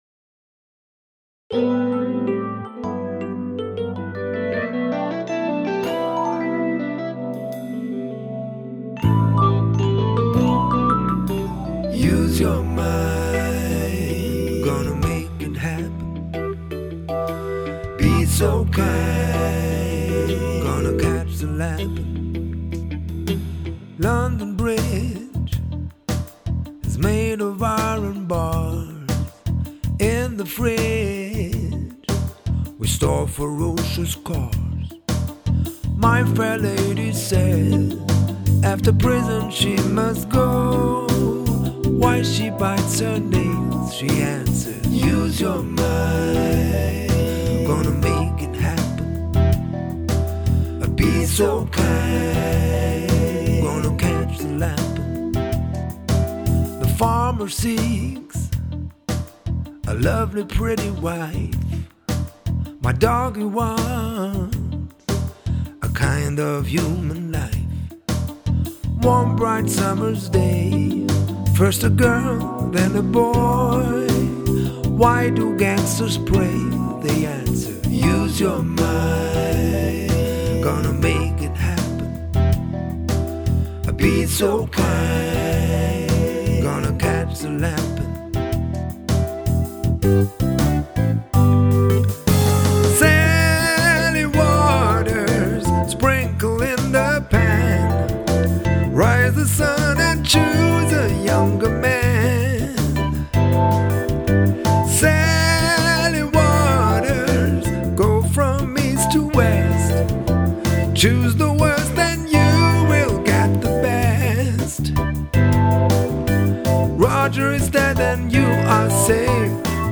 Zu guter Letzt: Alle Aufnahmen, die sie auf meiner homepage oder in der Rubrik hier "Unser Repertoire" hören können, sind Originalaufnahmen von meiner tatsächlichen eigenen Performance.